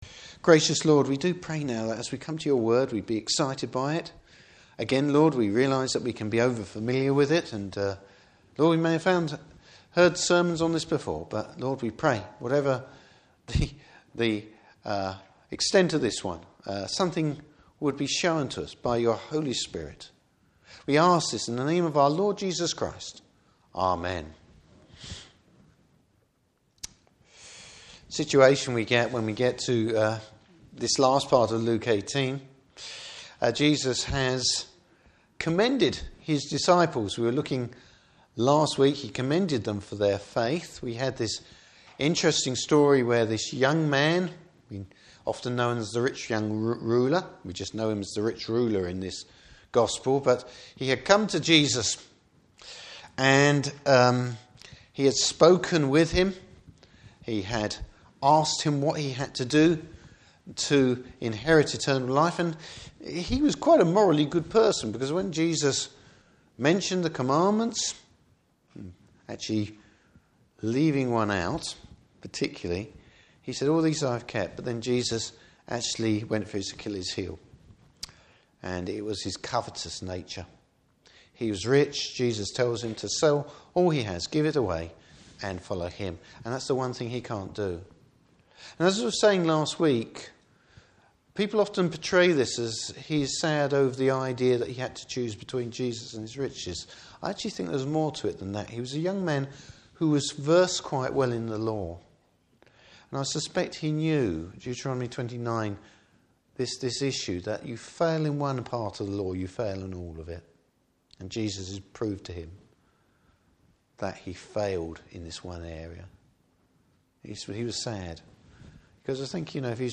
Service Type: Morning Service Bible Text: Luke 18:31-43.